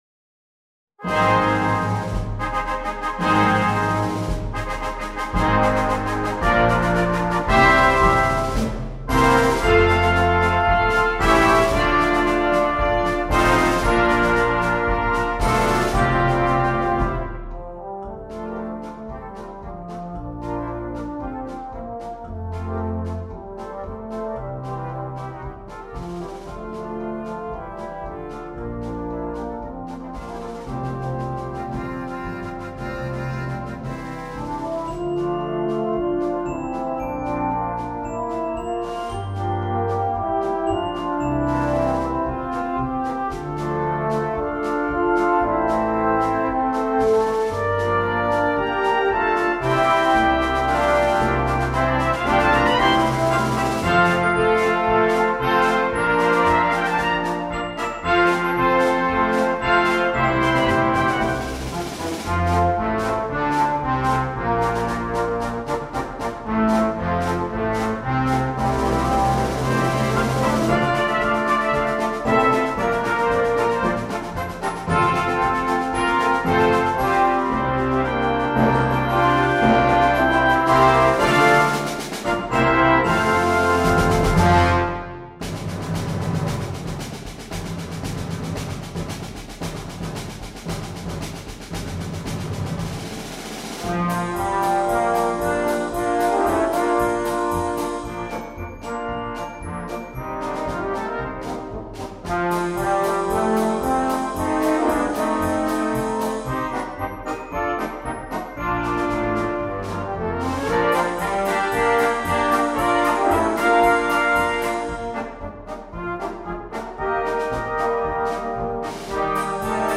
marches
Demo Track: Wind Band Version!